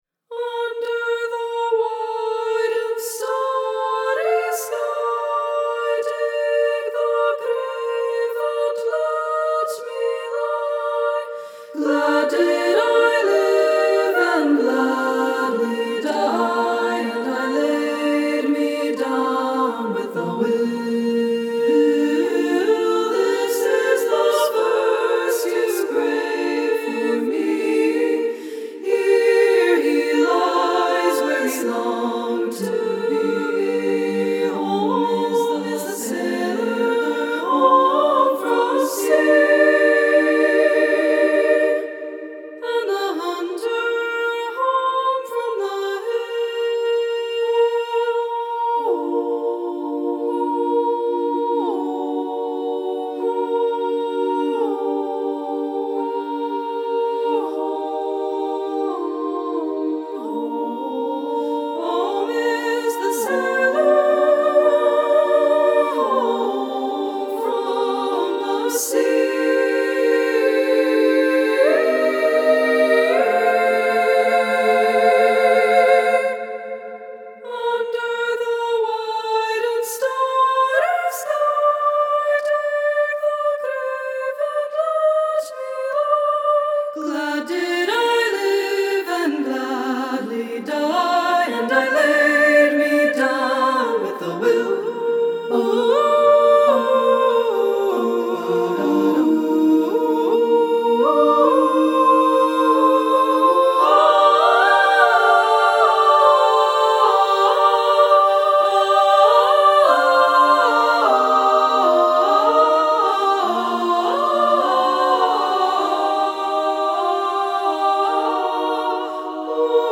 (demo recording from the composer).
for SSAA a cappella choir
Inspired by Irish folk music,